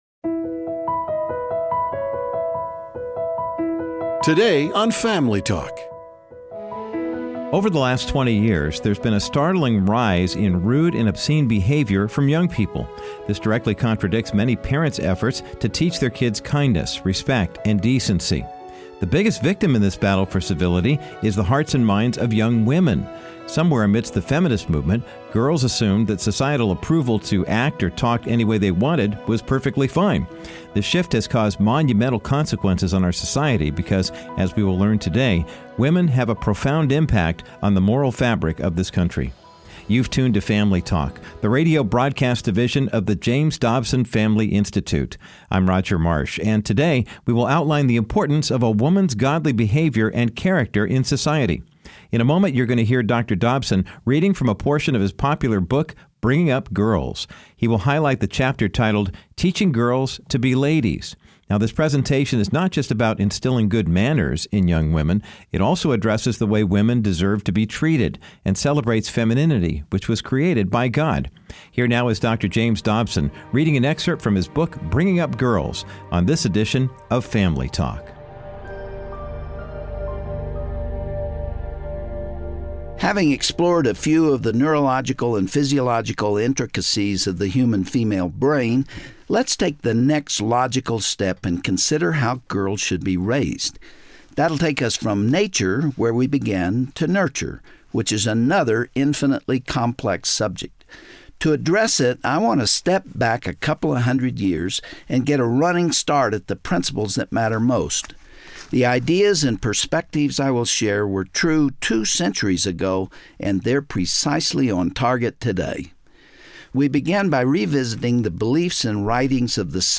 Over the last 2 decades, children, especially girls, have been taught less and less about the importance of manners and civility. On this Family Talk broadcast, Dr. Dobson will read a chapter from his popular book, Bringing Up Girls.